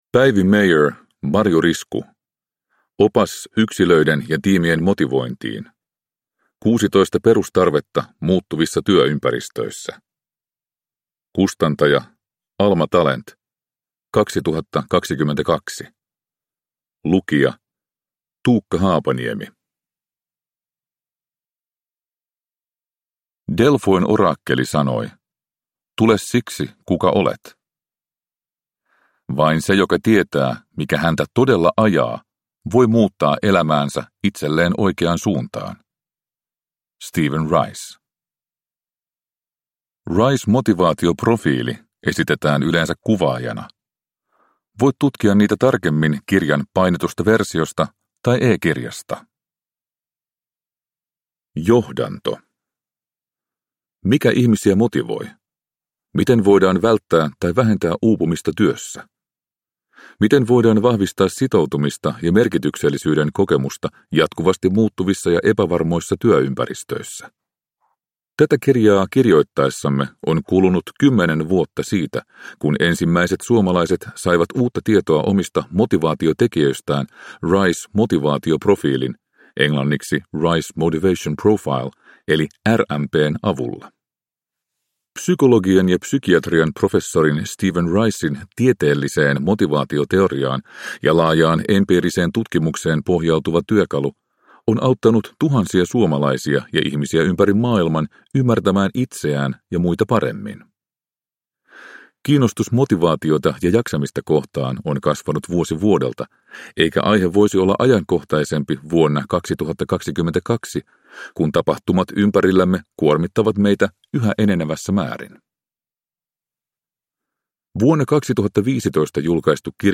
Opas yksilöiden ja tiimien motivointiin – Ljudbok – Laddas ner